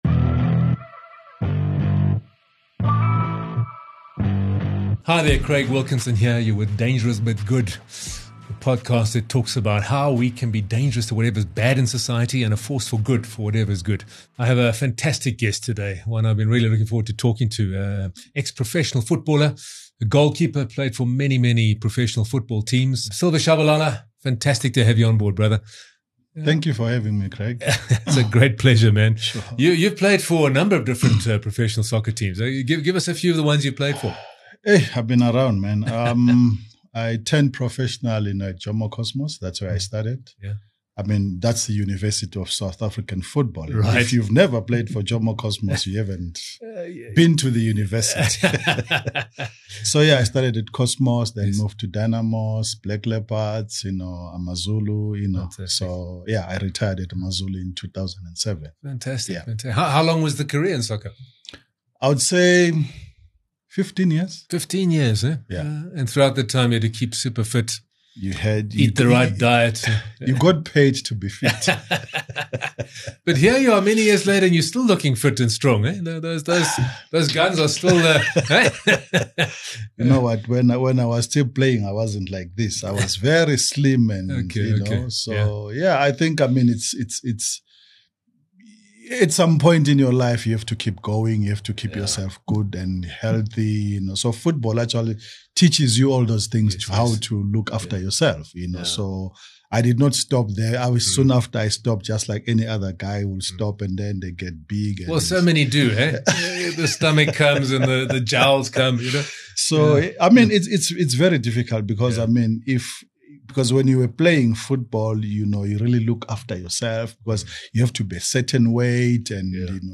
In this raw and honest conversation